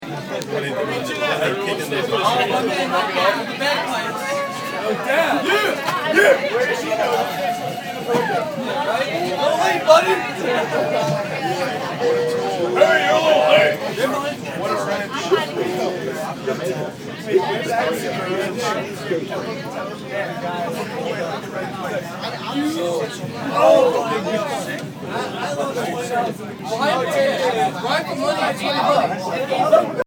Field Recording 7
Location: LIRR on St. Patricks Day
LIRR.mp3